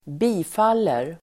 Uttal: [²b'i:fal:er]